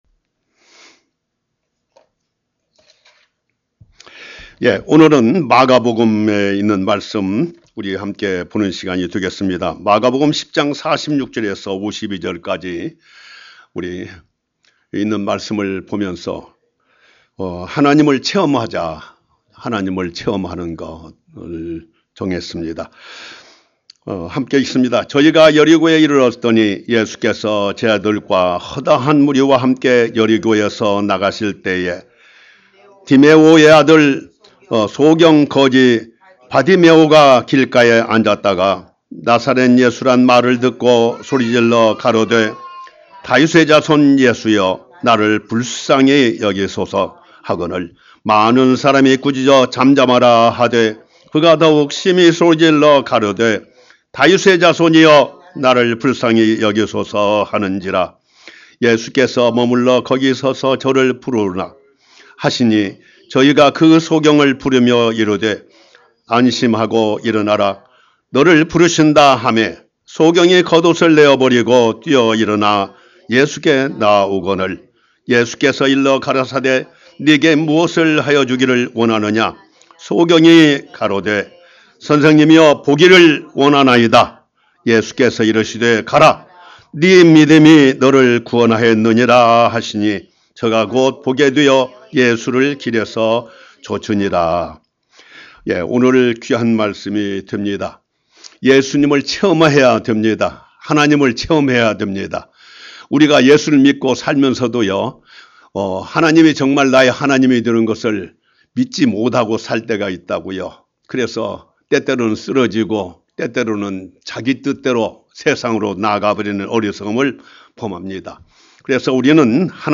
Sermon - 하나님을 체험하라 Experience God